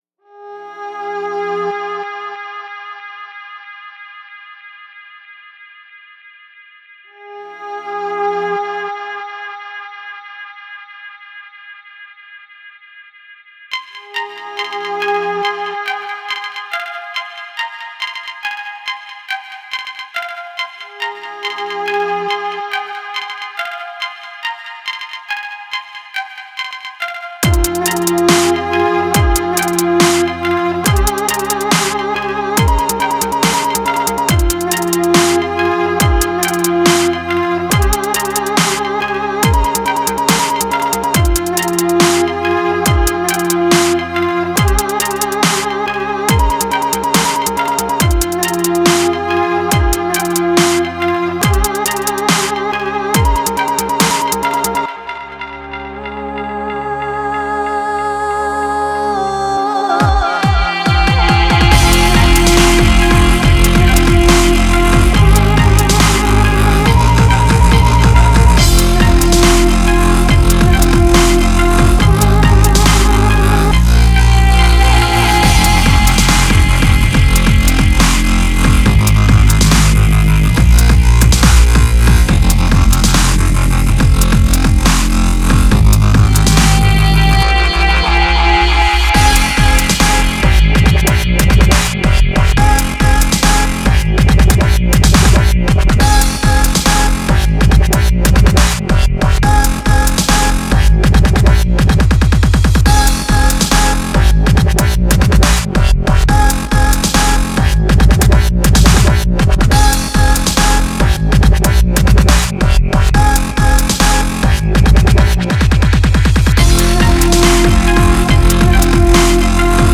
Nice Dubstep song.